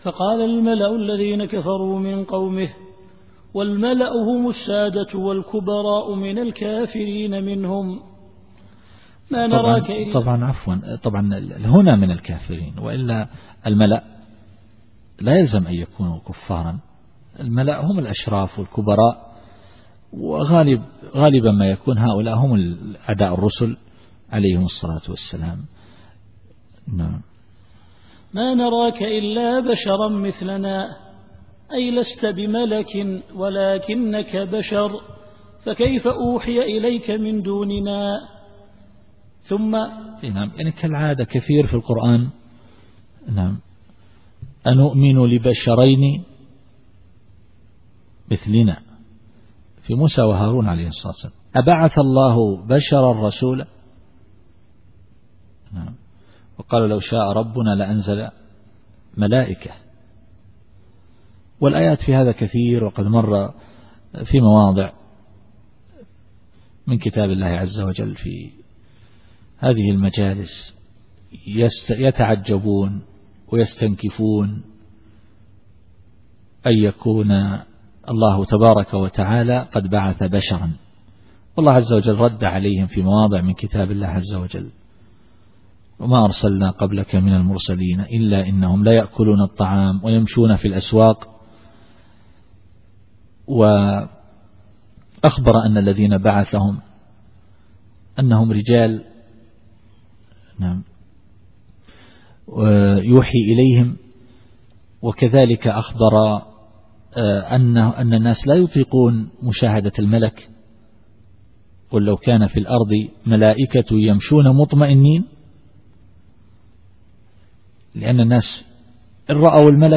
التفسير الصوتي [هود / 27]